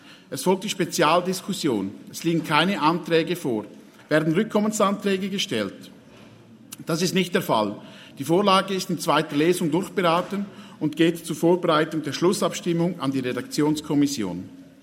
Session des Kantonsrates vom 12. bis 14. Juni 2023, Sommersession